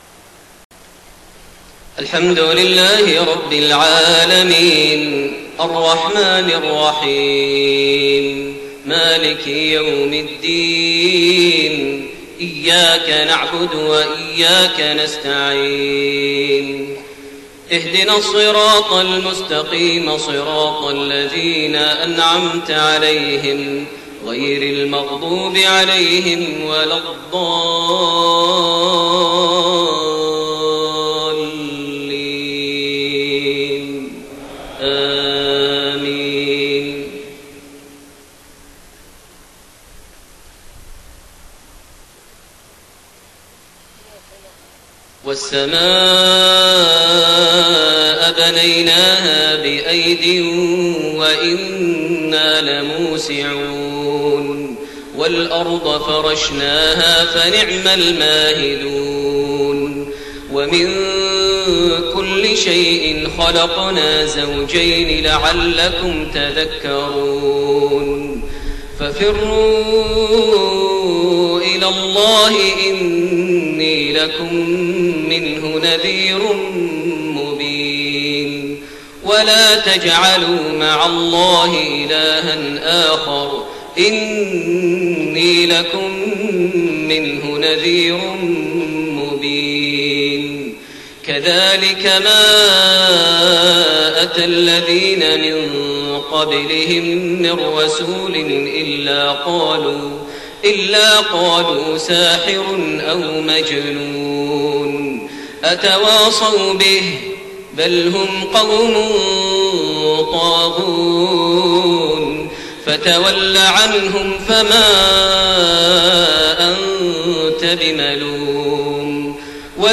صلاة المغرب8-7-1431 من سورة الذاريات47-60 > 1431 هـ > الفروض - تلاوات ماهر المعيقلي